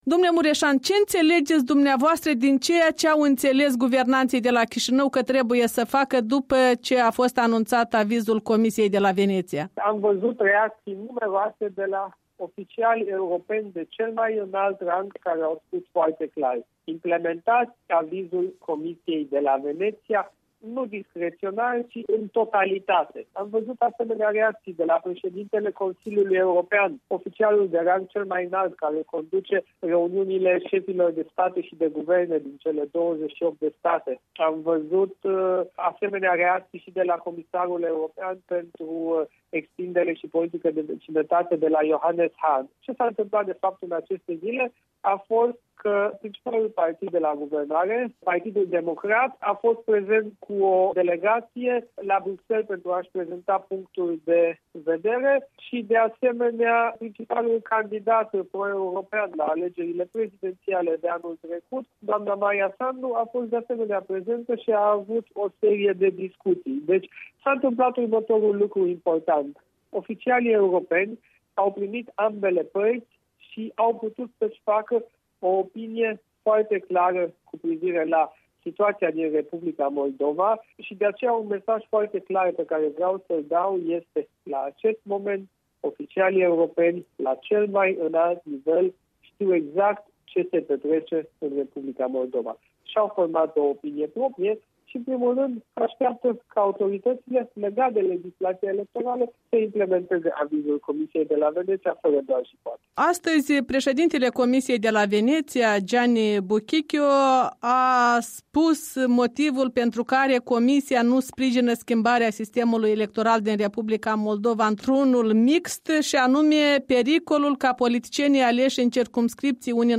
Interviu cu europarlamentarul Siegfried Mureșan